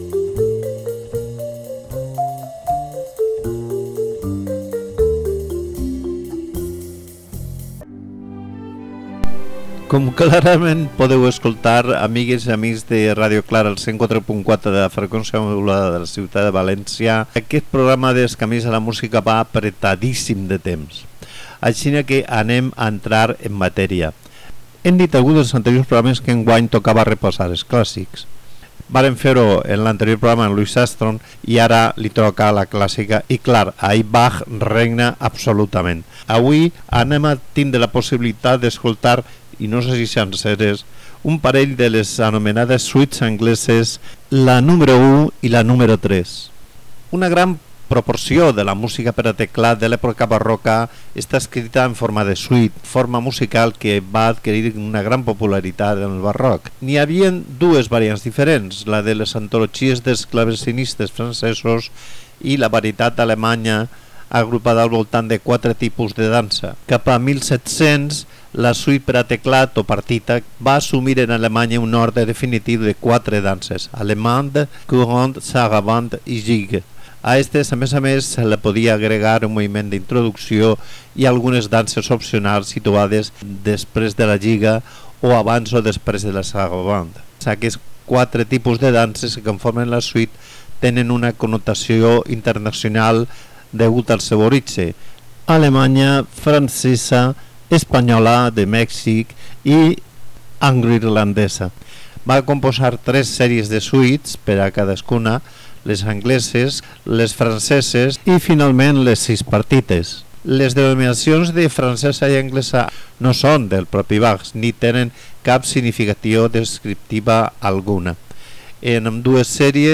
per a clavecí